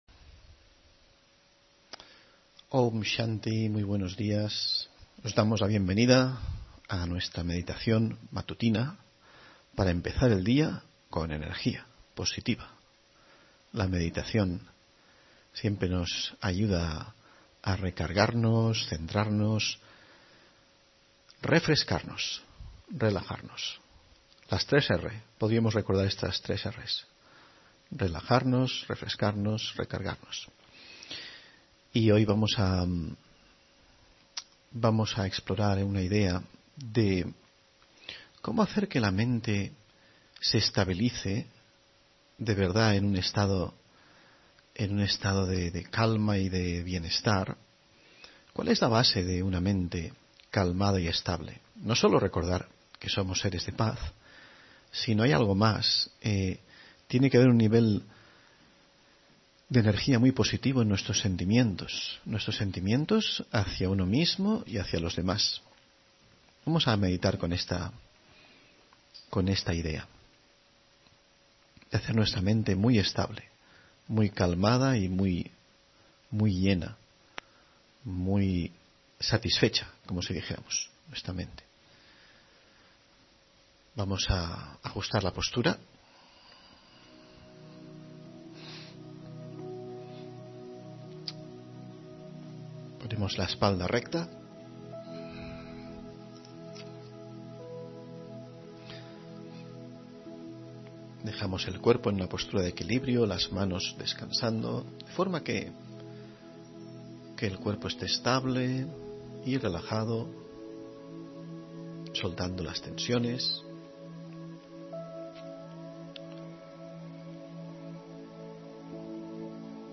Meditación y conferencia: El hilo invisible de la justicia (13 Noviembre 2023)